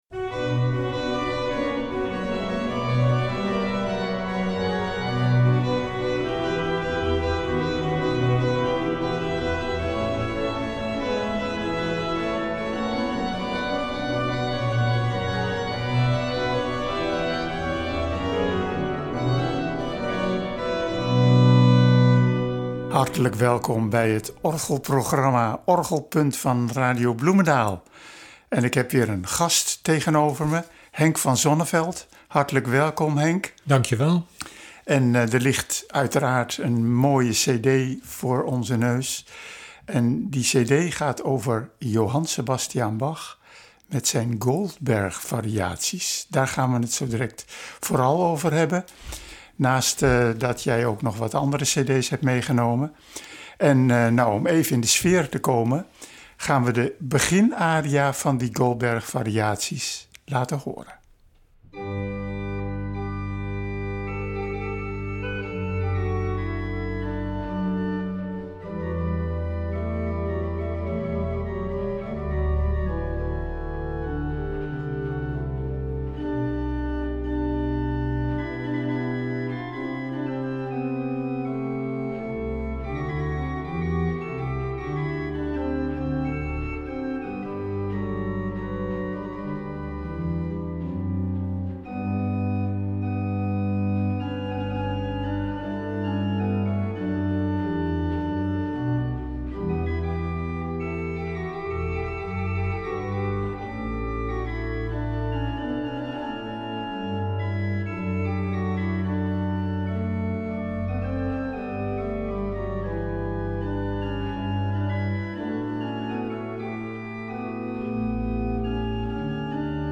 Op orgel zijn er natuurlijk meer mogelijkheden om in de klank en sterkte te variëren.
En dat klinkt overtuigend en fraai in de akoestiek van die kerk.